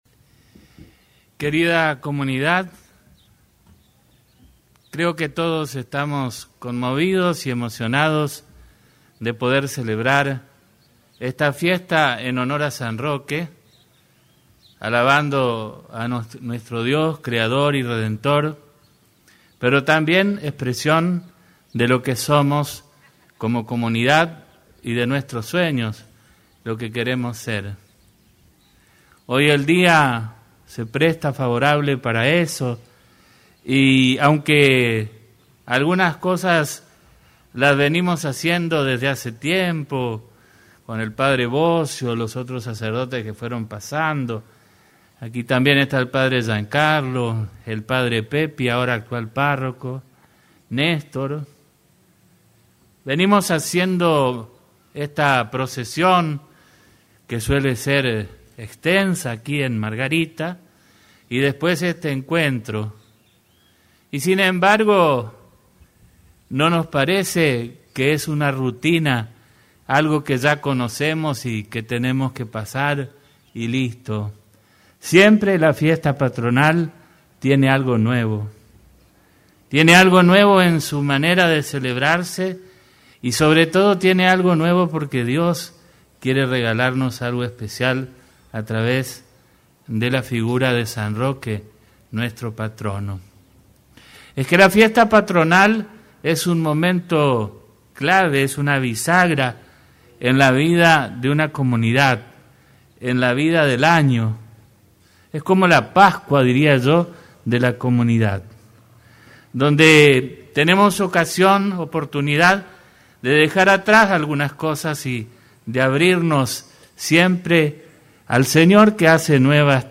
Durante su homilía, el obispo diocesano instó a los fieles a ser proactivos en la ayuda a los necesitados y subrayó la importancia de mantener el buen humor y la alegría, incluso en tiempos difíciles.
En la santa misa del 16 de agosto, Mons. Ángel José Macín destacó la importancia de este evento no solo como una celebración religiosa, sino como una bisagra en la vida comunitaria. Subrayó que la fiesta patronal debe ser vista como una oportunidad para dejar atrás viejas tensiones y abrirse a un nuevo comienzo, similar a una pascua.